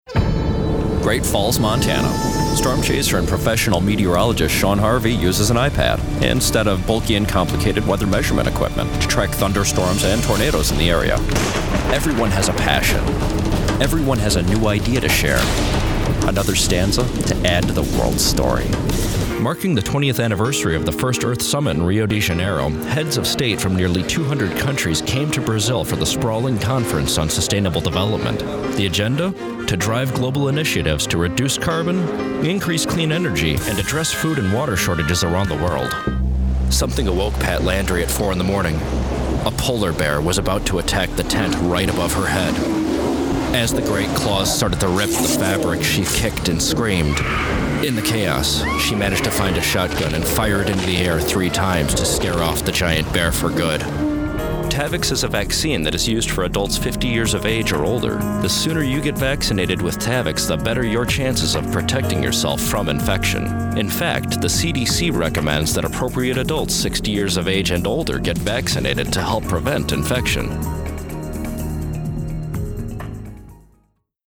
Narration Demo